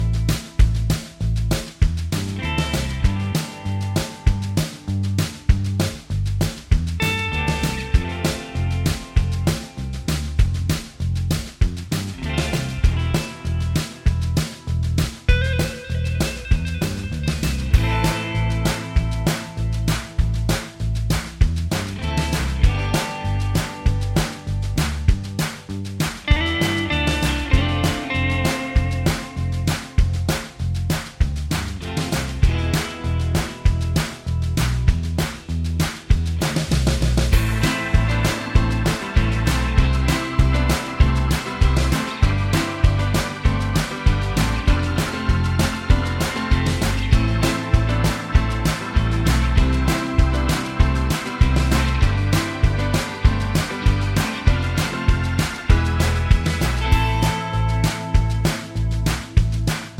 Minus Main Guitar For Guitarists 3:56 Buy £1.50